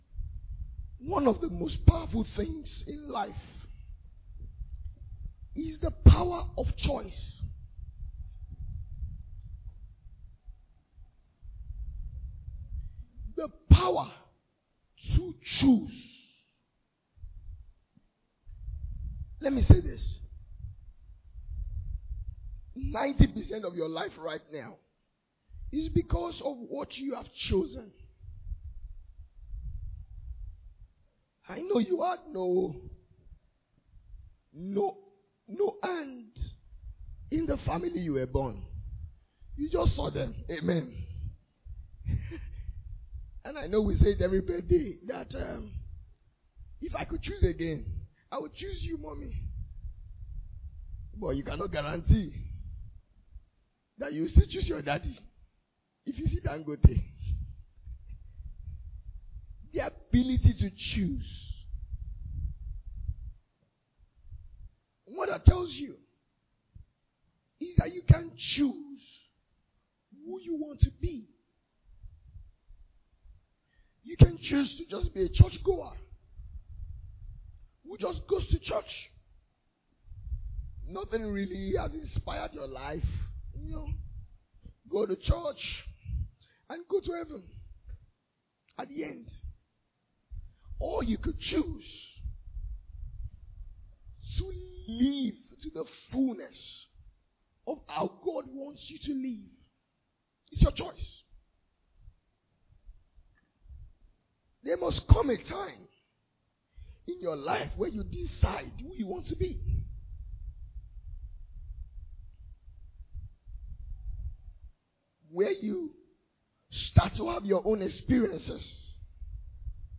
Message from our annaul Ministers’ Retreat 2025